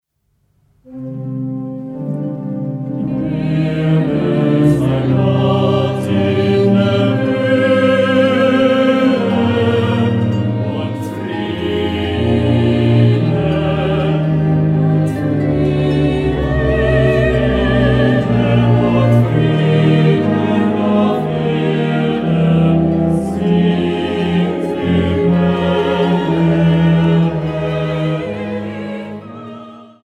Harfe
Violine 1
Viola
Violoncello